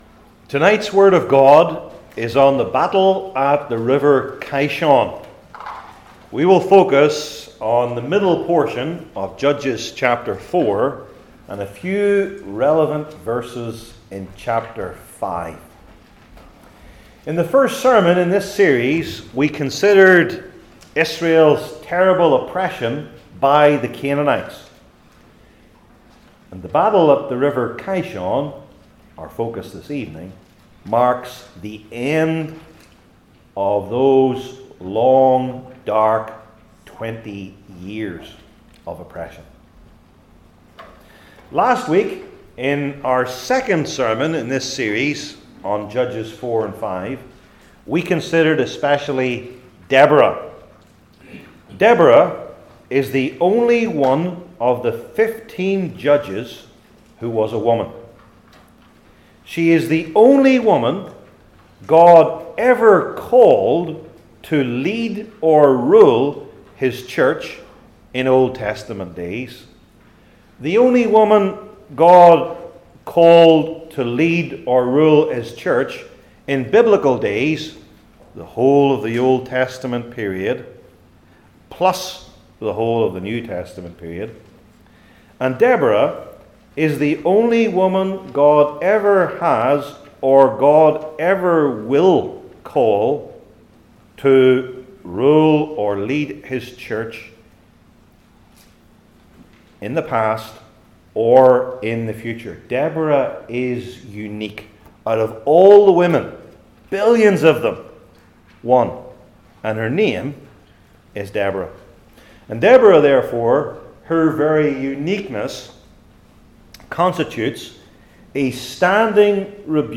Old Testament Sermon Series I. The Locations of the Various Parties II.